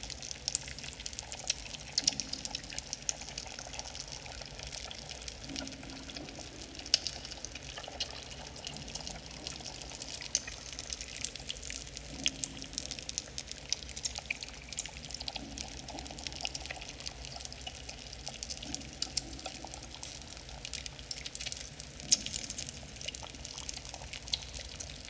cave_ambience_loop_02.wav